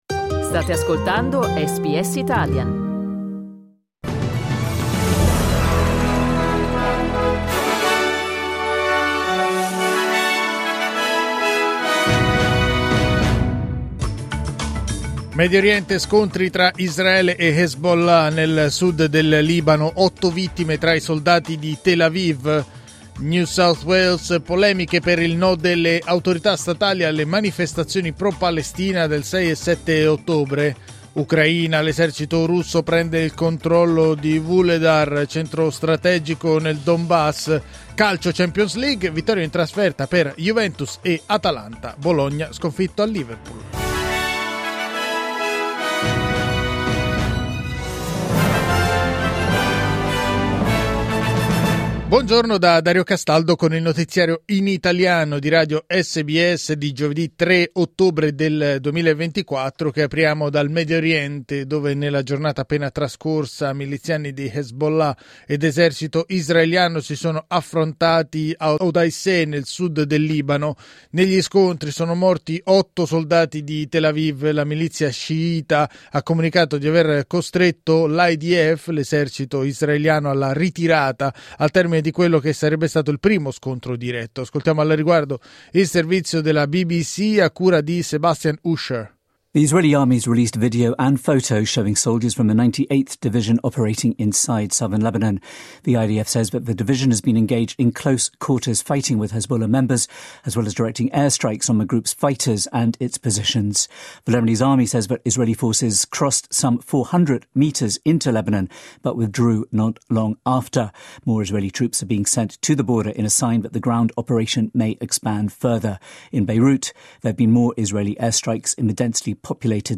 Il notiziario di SBS in italiano.